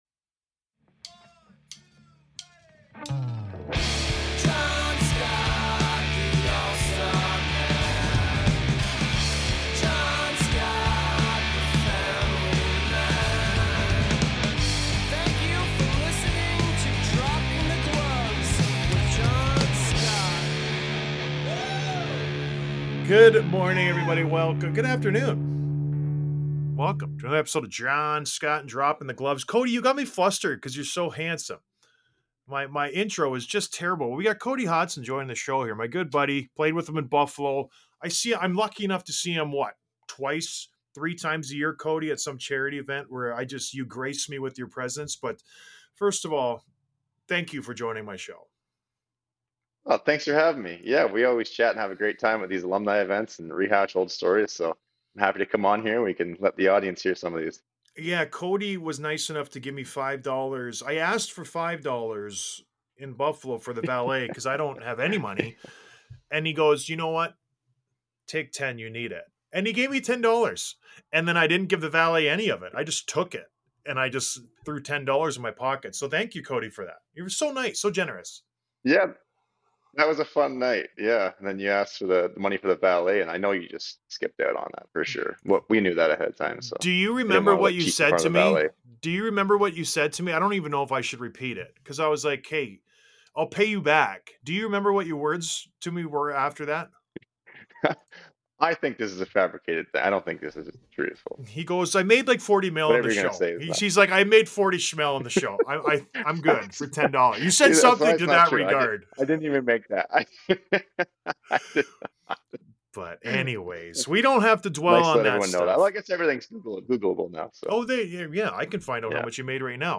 Interview w/ Cody Hodgson